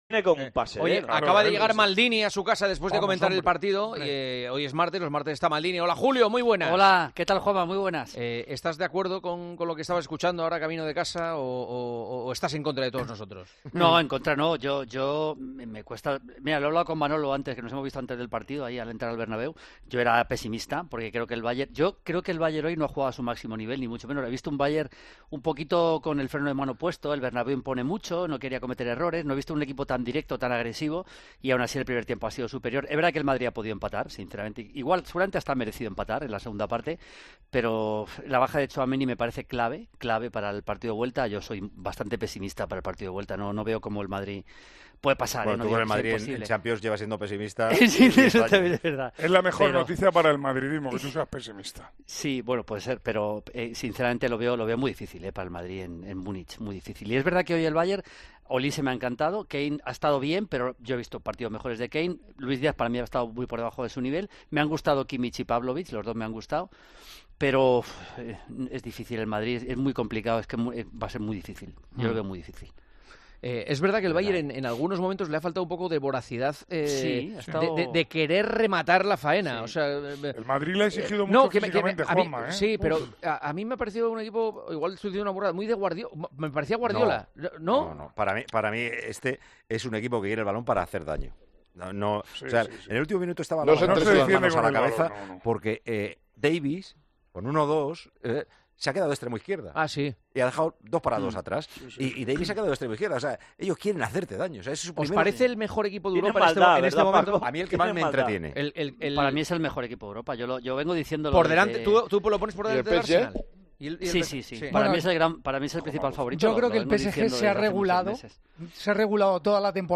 La discusión ha derivado en un debate sobre quién es el principal favorito para ganar la Champions.